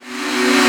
VEC3 FX Athmosphere 11.wav